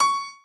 b_piano1_v100l4o7cp.ogg